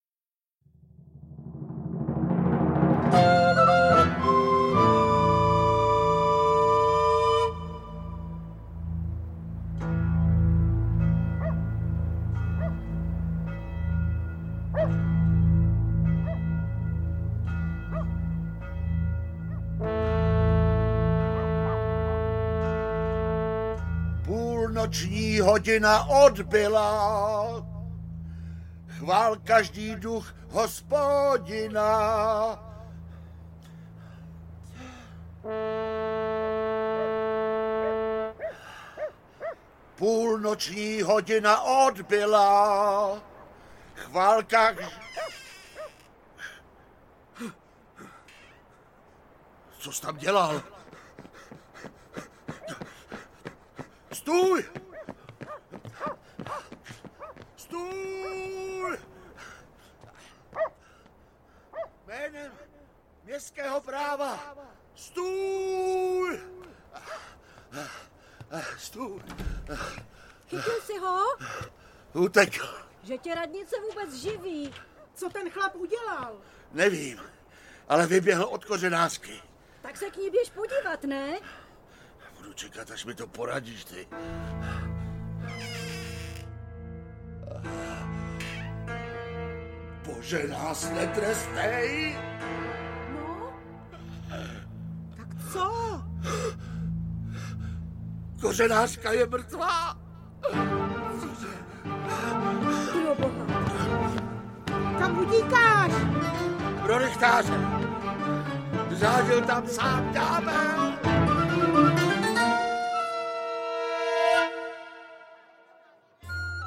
Ďáblův nápoj audiokniha
Ukázka z knihy